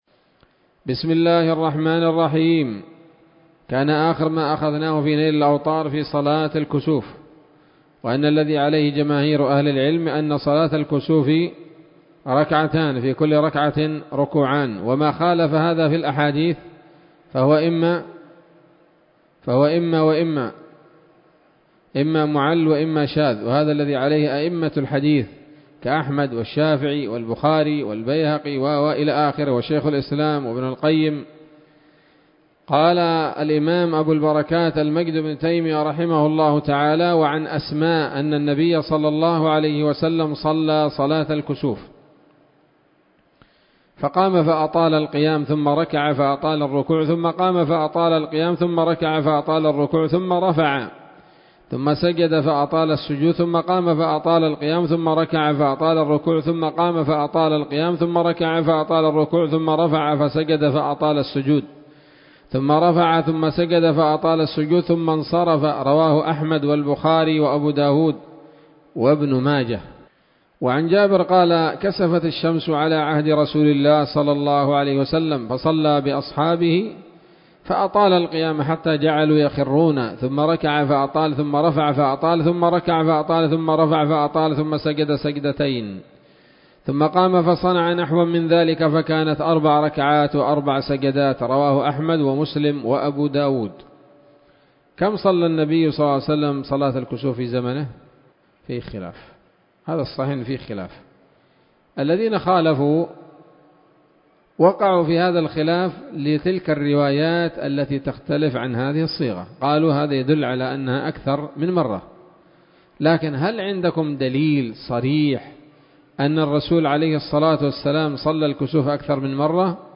الدرس الرابع من ‌‌‌‌كتاب صلاة الكسوف من نيل الأوطار